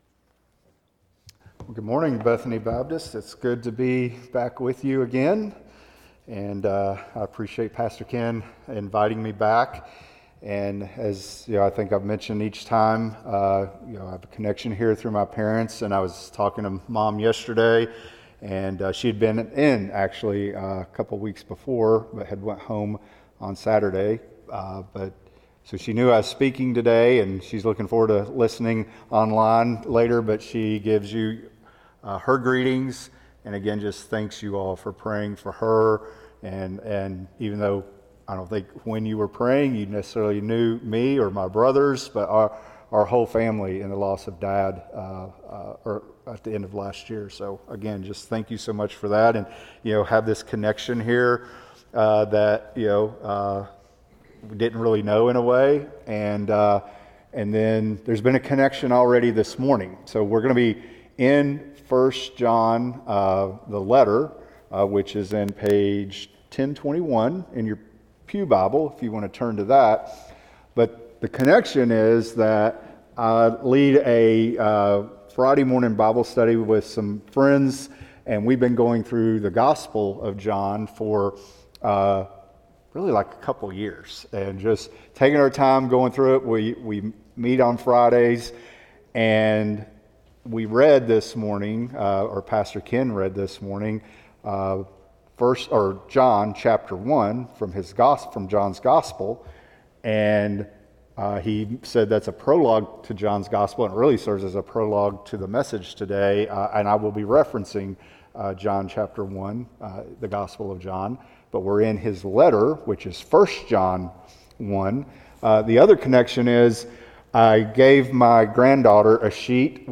1 John 1:1-4 Service Type: Sunday AM Topics: Christ's divinity , Christ's humanity , Salvation « God’s Glorious Gospel